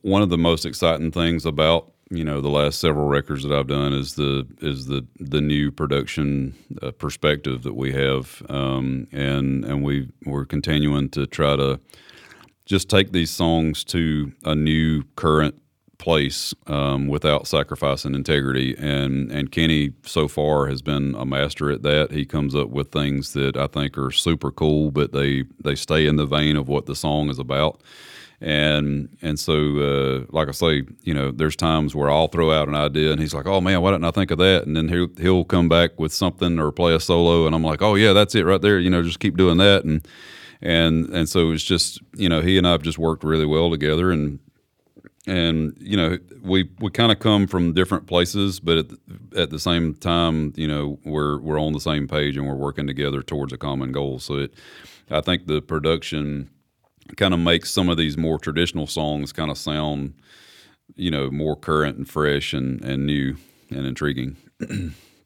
Josh Turner explains the new production perspective on recent projects, including his tenth studio album, This Country Music Thing, coming this Friday, August 16th.